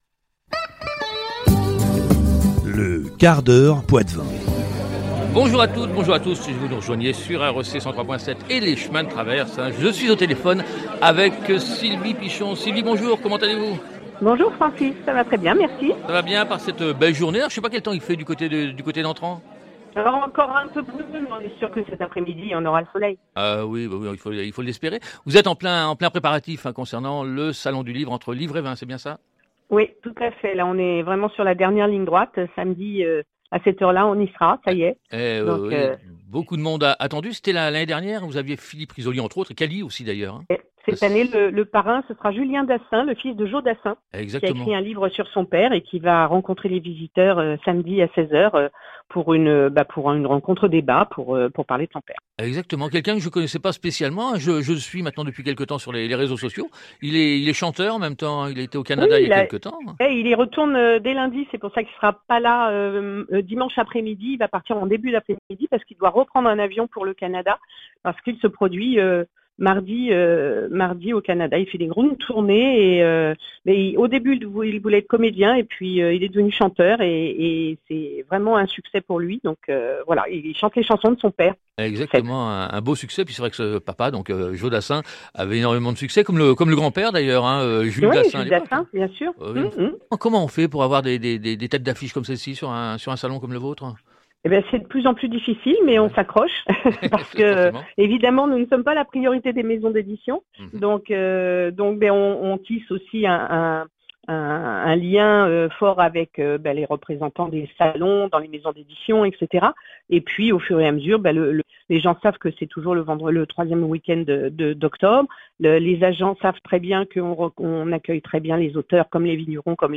Le quart d’heure Poitevin – Interview